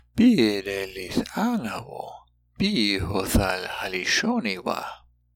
When paragraphs of Láadan are given, sound files will be provided for the entire paragraph as well as each sentence.